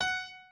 pianoadrib1_5.ogg